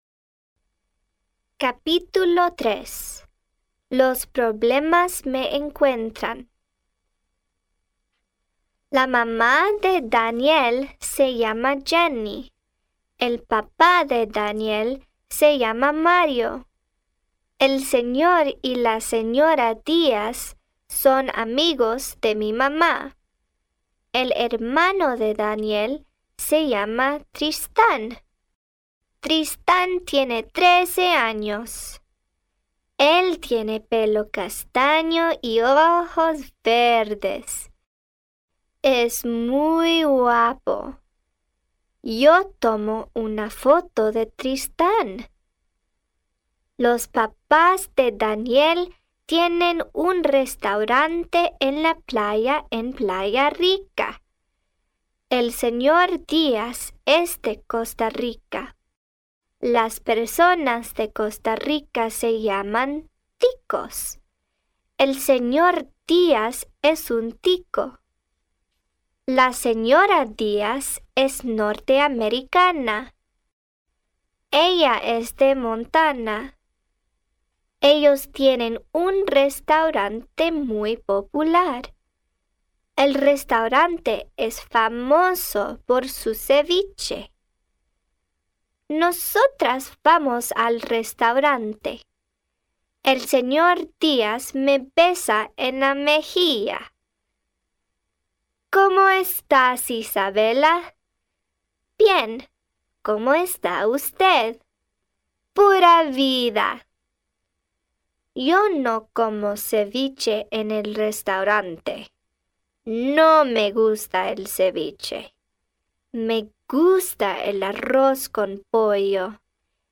Audiobook with purchase of Isabela captura un congo